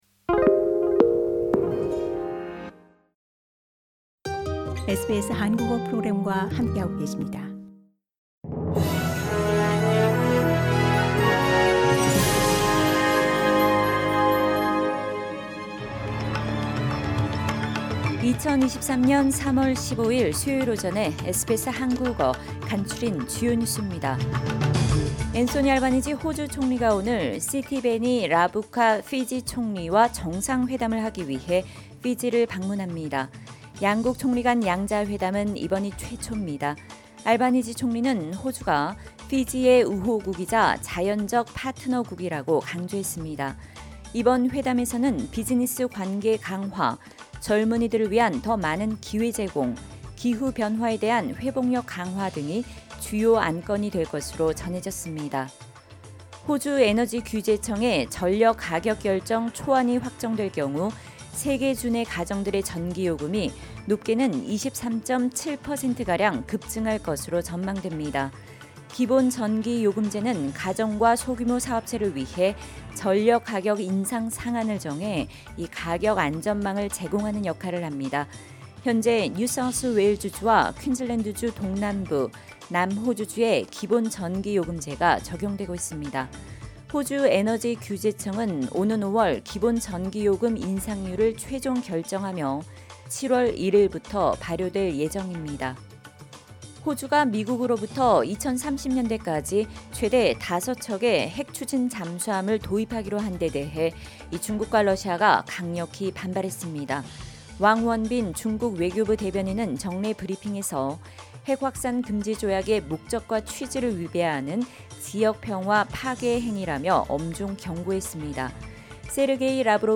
SBS 한국어 아침 뉴스: 2023년 3월 15일 수요일
2023년 3월 15일 수요일 아침 SBS 한국어 간추린 주요 뉴스입니다.